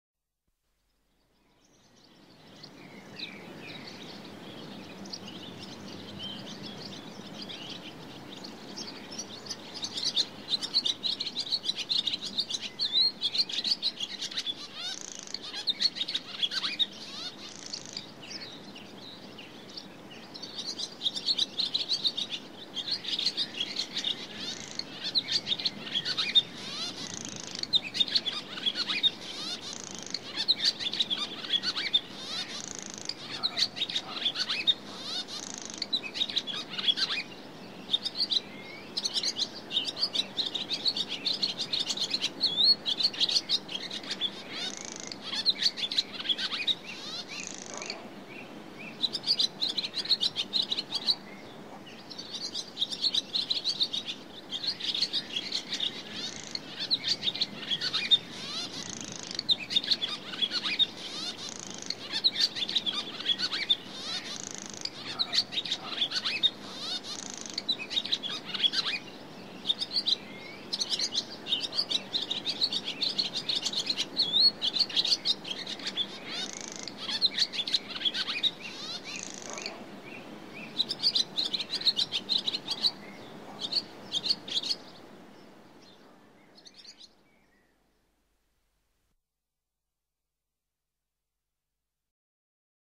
دانلود آهنگ پرستو از افکت صوتی انسان و موجودات زنده
جلوه های صوتی
دانلود صدای پرستو از ساعد نیوز با لینک مستقیم و کیفیت بالا